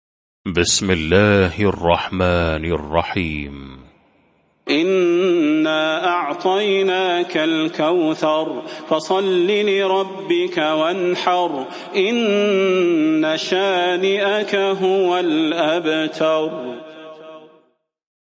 المكان: المسجد النبوي الشيخ: فضيلة الشيخ د. صلاح بن محمد البدير فضيلة الشيخ د. صلاح بن محمد البدير الكوثر